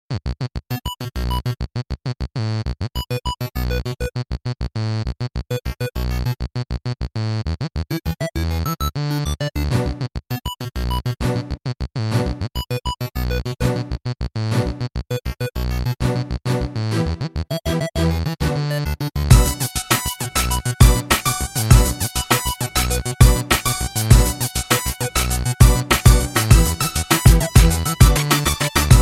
fantasy-music_25303.mp3